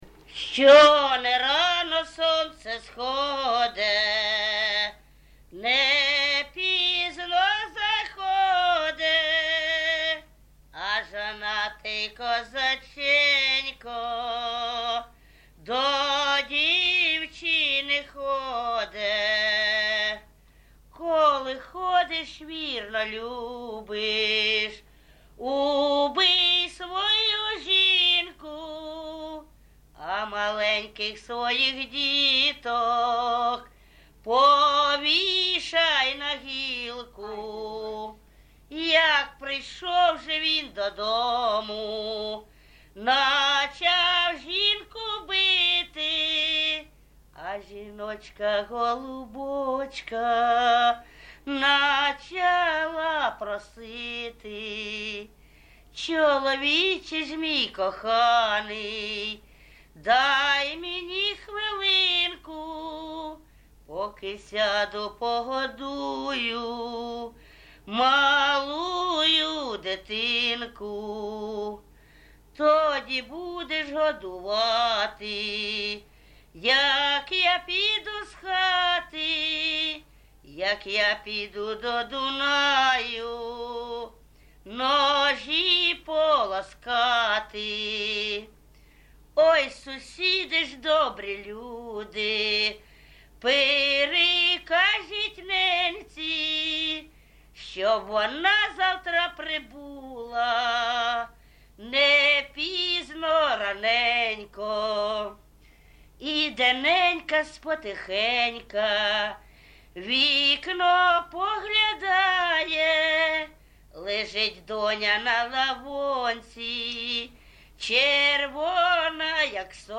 ЖанрПісні з особистого та родинного життя, Балади
Місце записус. Гарбузівка, Сумський район, Сумська обл., Україна, Слобожанщина